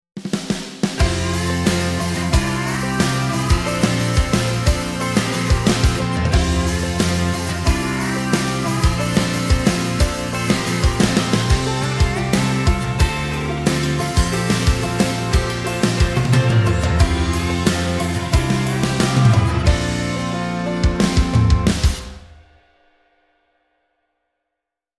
3. 打击类
Drum Daddy是一款全新的满载虚拟鼓乐器，包含各种风格的样本。
• 基于精心采样的鼓组，声音现代强劲多样化